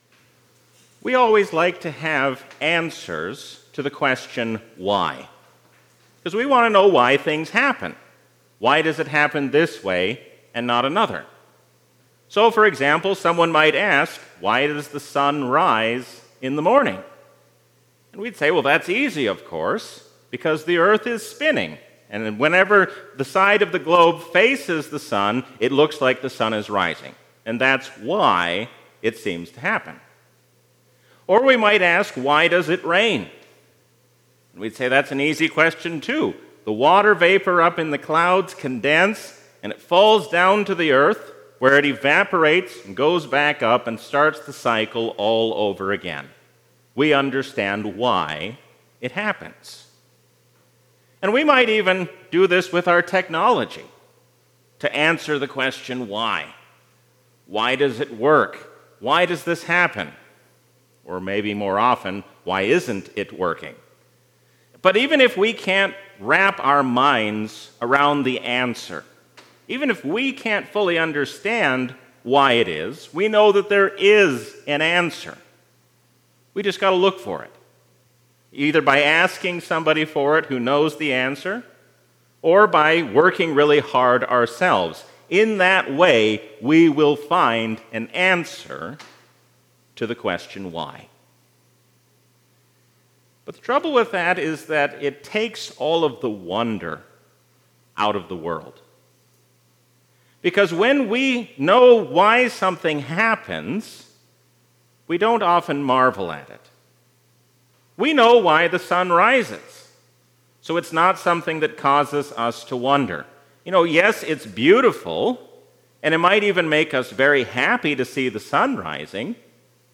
A sermon from the season "Trinity 2024." The face of Moses shines to show us that we should listen to God.